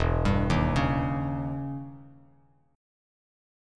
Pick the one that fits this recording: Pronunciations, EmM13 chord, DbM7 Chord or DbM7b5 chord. EmM13 chord